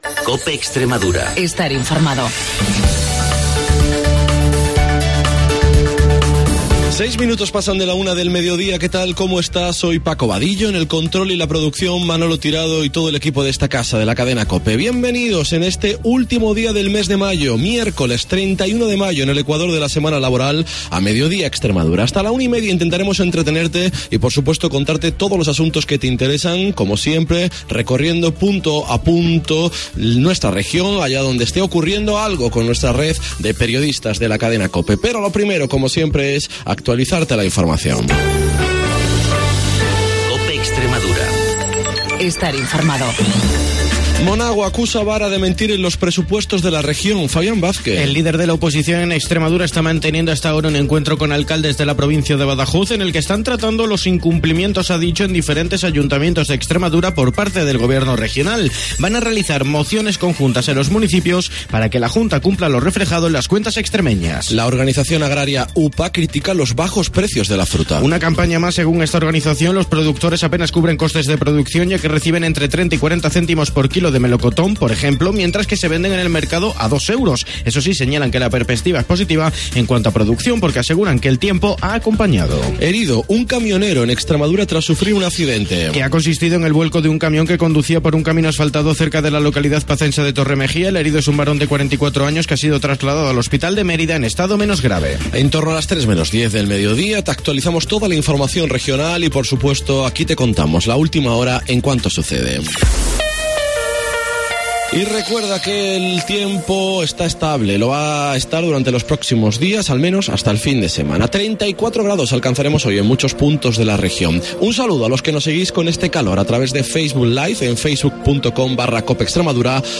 En el programa de hoy nos hemos ido en directo a la Feria del Libro de Mérida